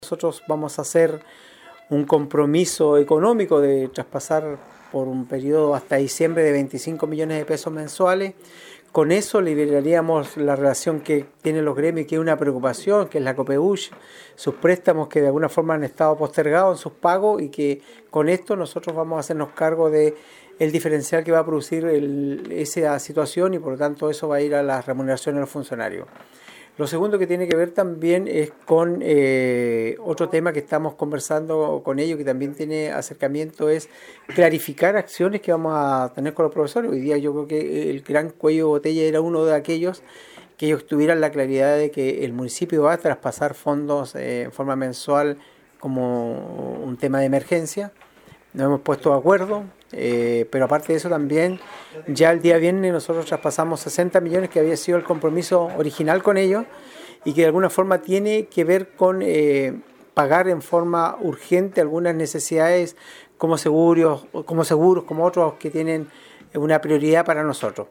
En la ocasión, el alcalde René Garcés informó de un programa de entrega de recursos financieros a la corporación por este año 2023, lo que significará 25 millones de pesos mensuales hasta el mes de diciembre, entre otros, señaló el edil.
04-ALCALDE-RENE-GARCES.mp3